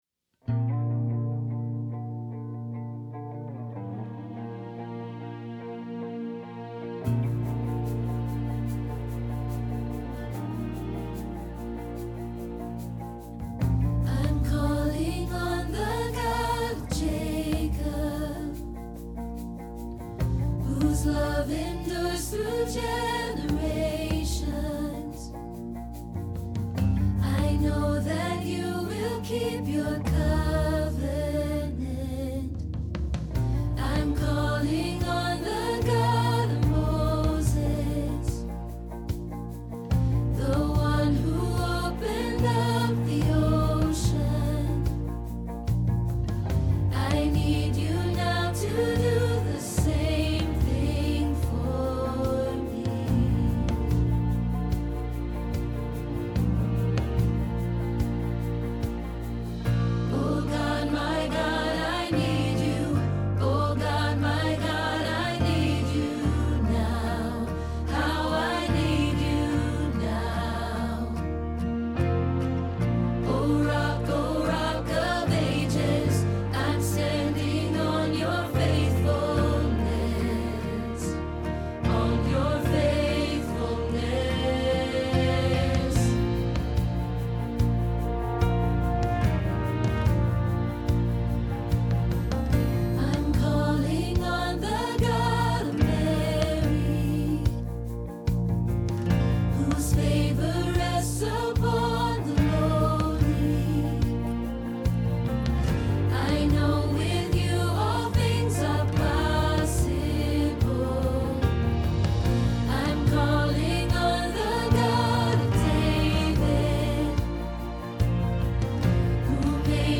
Same God – Soprano – Hilltop Choir